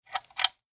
Desk telephone Fg tist 264 b
Bell volume control
0038_Lautstaerkeregelung_der_Klingel.mp3